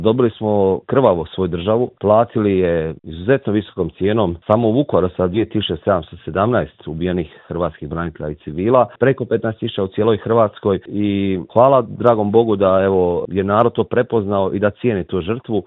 Vukovarski gradonačelnik Marijan Pavliček u Intervjuu Media servisa kaže da je grad već danima pun hodočasnika: